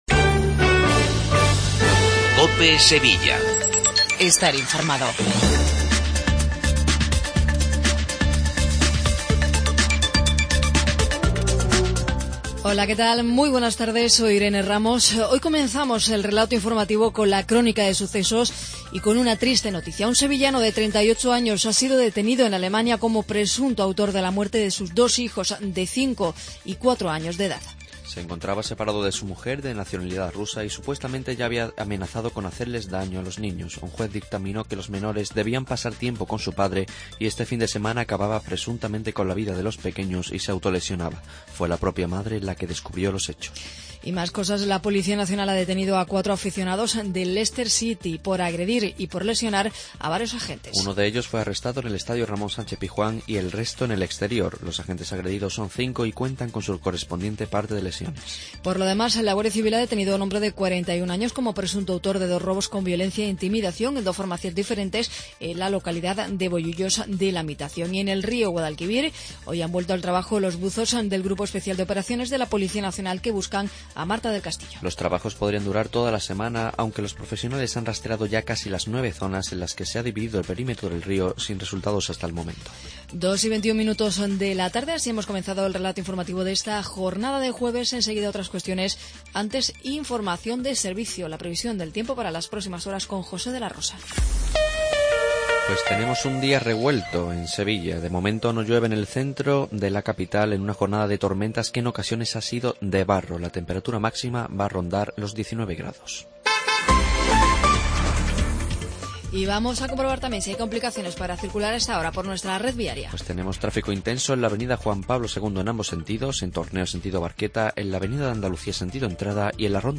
INFORMATIVO LOCAL MEDIODIA COPE SEVILLA